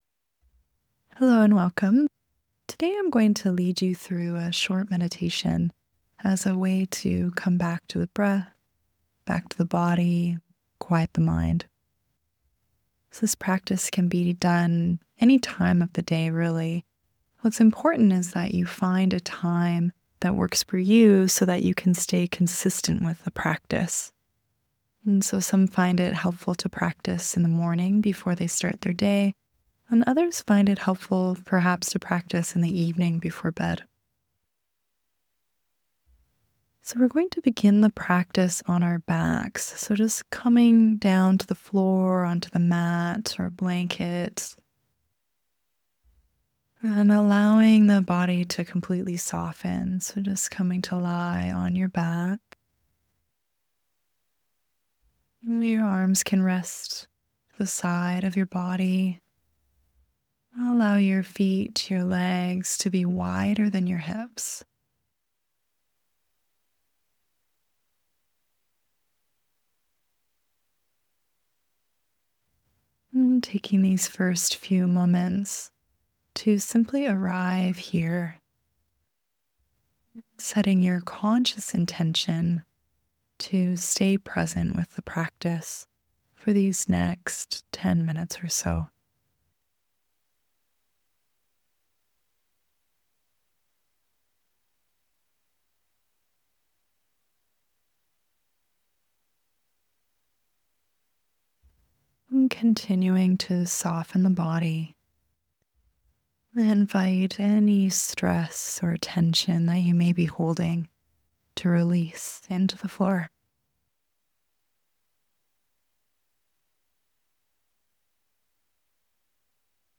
Below you’ll find a short 15-minute meditation for guidance.